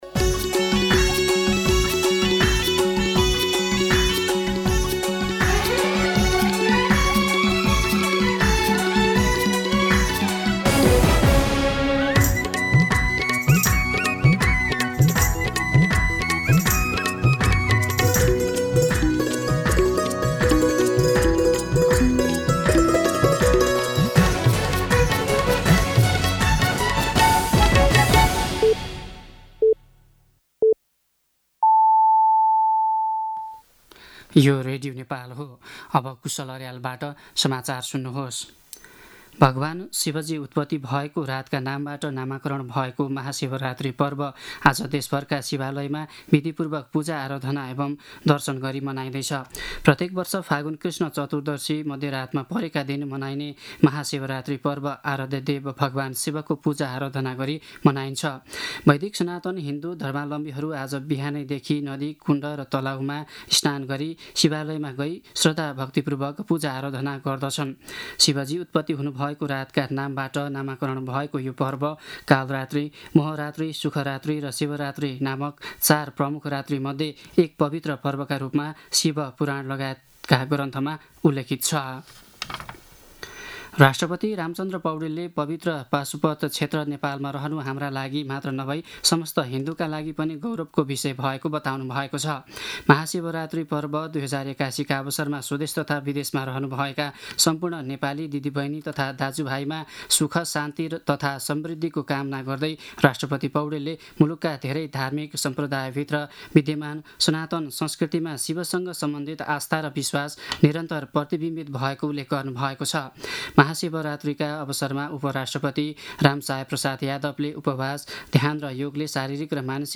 मध्यान्ह १२ बजेको नेपाली समाचार : १५ फागुन , २०८१